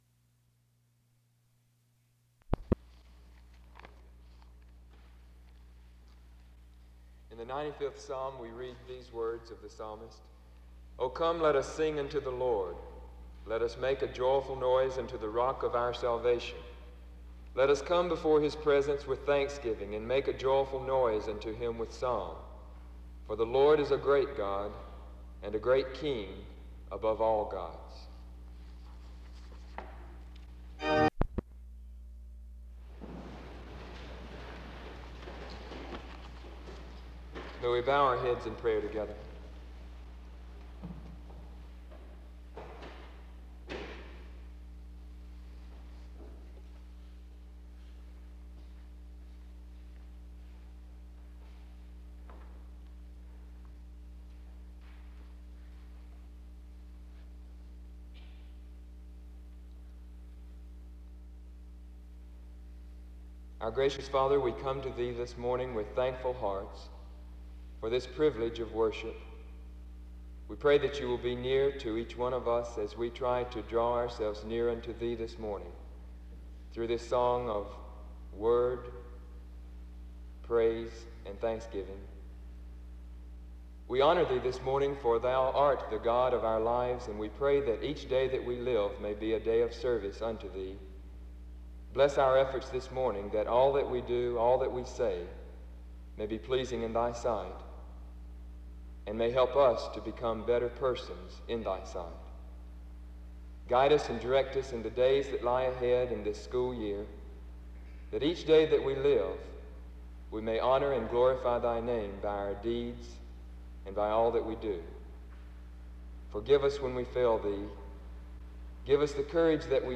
The service begins with the reading of Psalm 95:1-3 (00:00-00:36), a prayer (00:37-02:44), and responsive reading #26 (02:45-05:04).
Worship